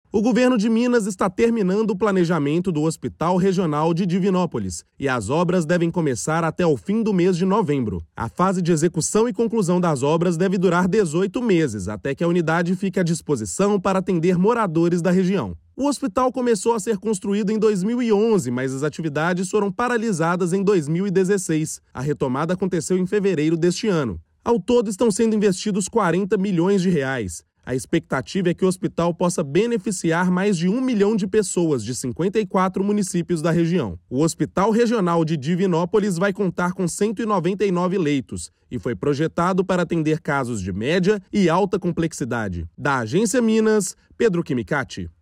Fase de planejamento está sendo finalizada e obras devem começar até o final de novembro. Ouça matéria de rádio.